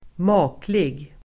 Uttal: [²m'a:klig]